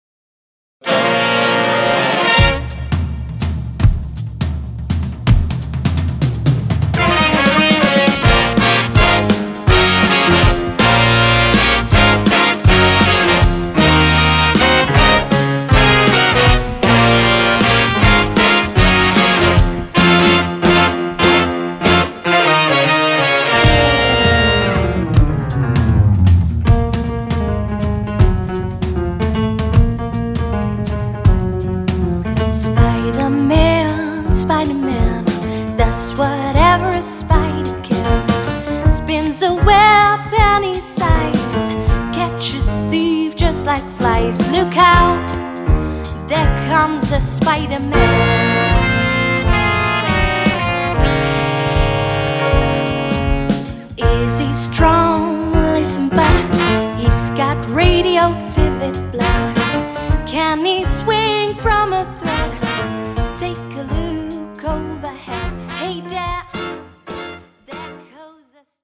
jeugd Big Band